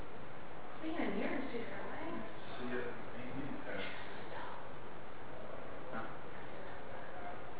Just Stop EVP